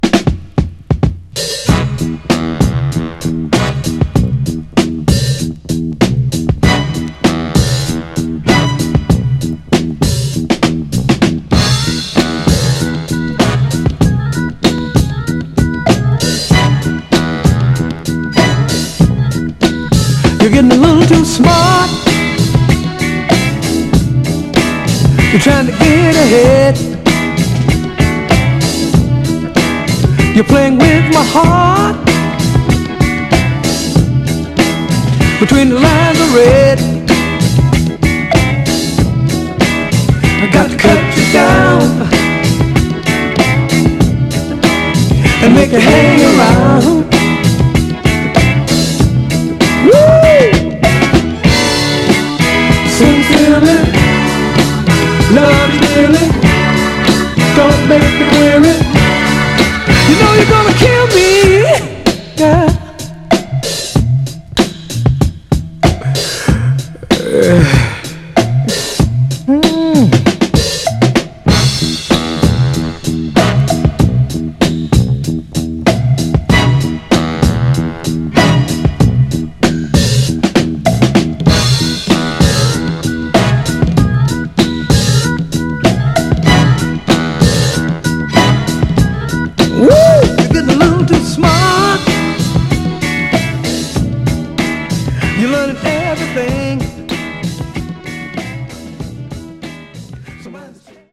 盤はいくつか細かいスレ箇所ありますが、グロスがありプレイ良好です。
※試聴音源は実際にお送りする商品から録音したものです※